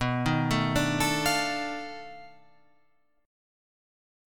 B7#9b5 Chord
Listen to B7#9b5 strummed